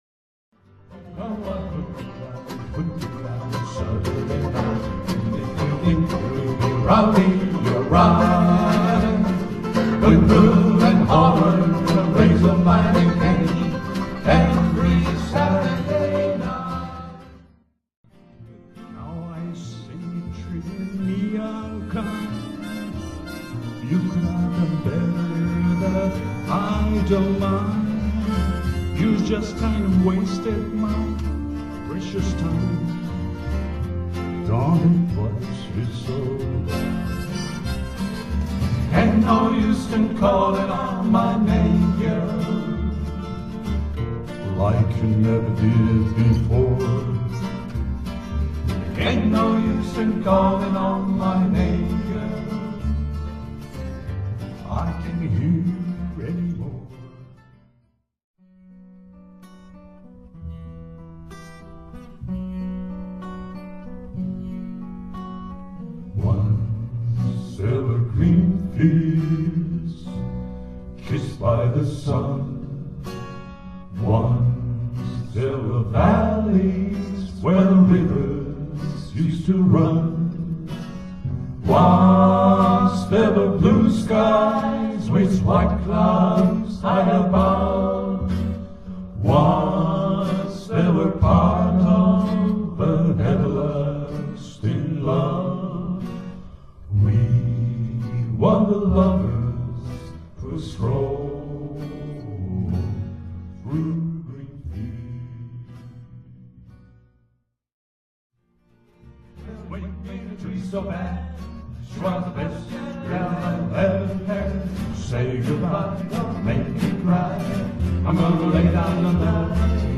ライブ目前練習
ライブまで一週間を切った１２日、最期の練習をしました。